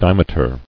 [dim·e·ter]